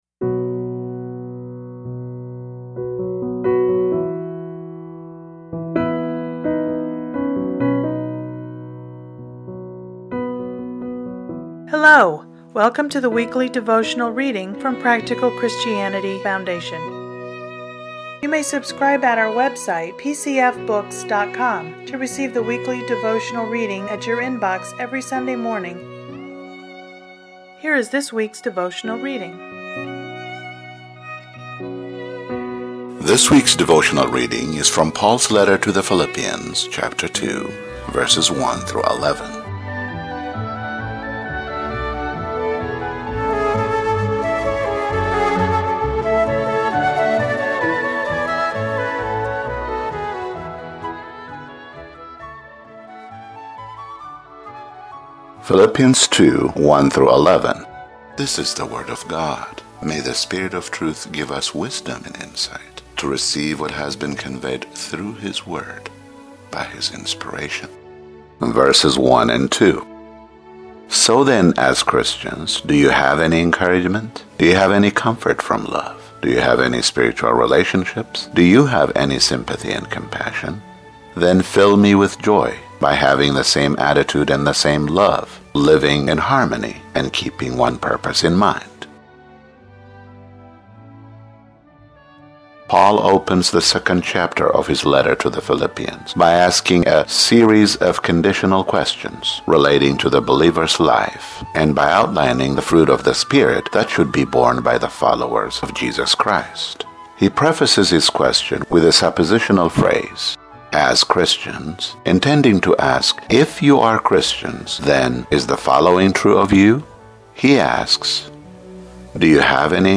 Listen to today's devotional commentary